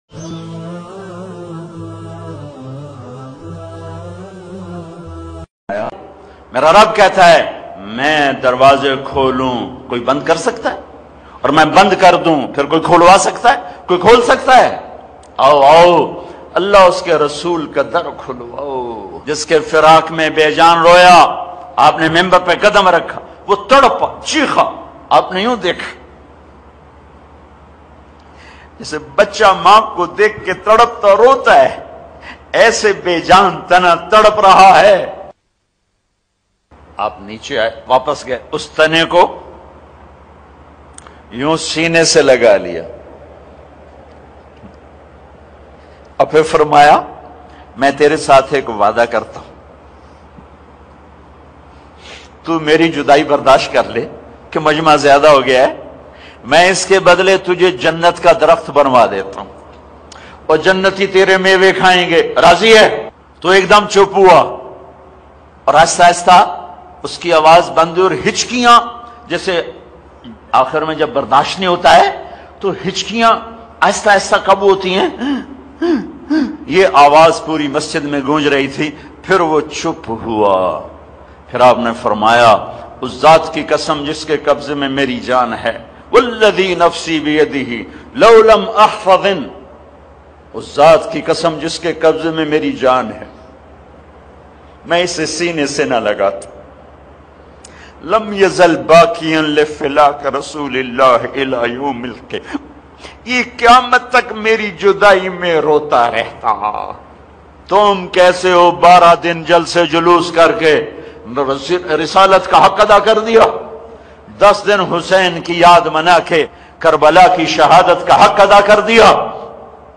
12 Rabi ul Awal ki Aamad bayan
12-Rabi-Ul-Awwal-Bayan-tj.mp3